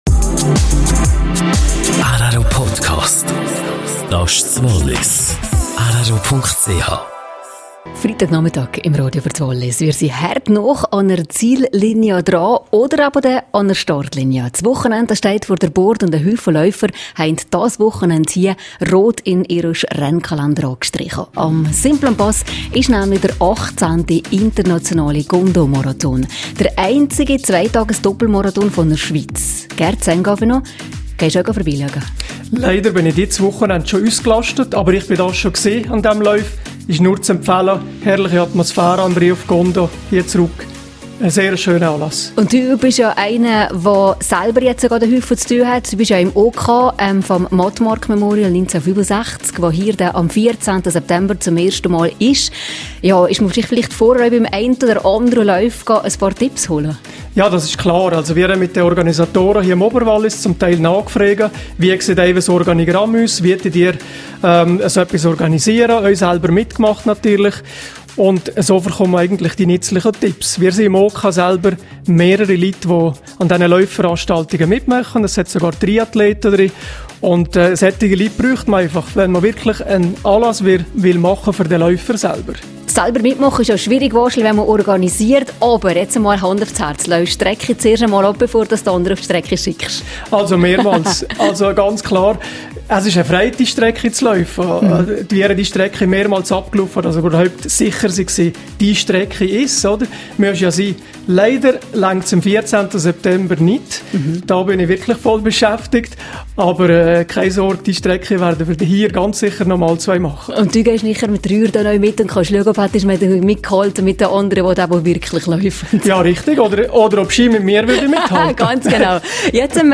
Radiostudio